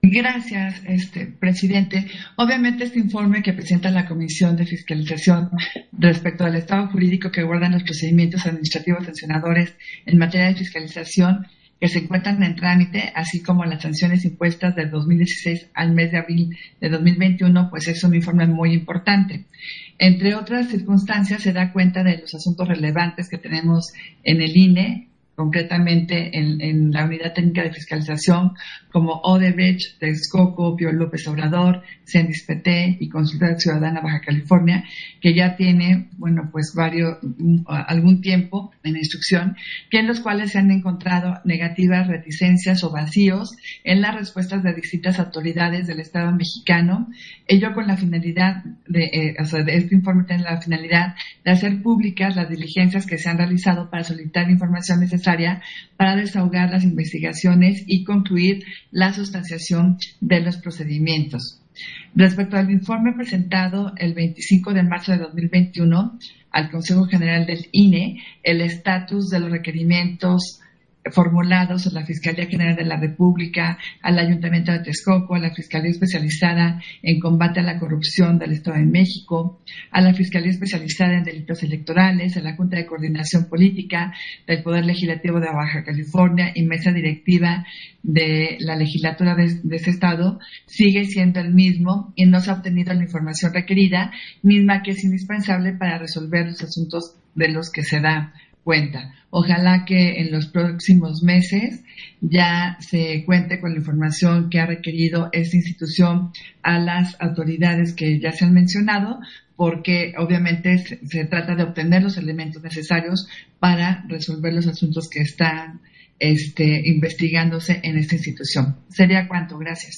Intervención de Adriana Favela, en Sesión Ordinaria, relativo al informe del estado jurídico que guardan los procedimientos administrativos sancionadores en materia de fiscalización